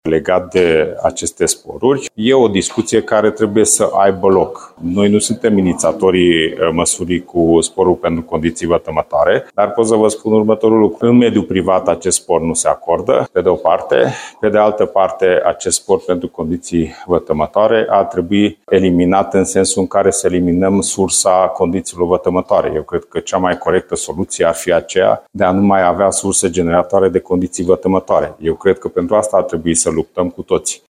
Prezent la inaugurarea unei creșe în localitatea Pâncota, județul Arad, Cseke Attila, a făcut o comparație cu munca în mediul privat.